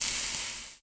sizzle.ogg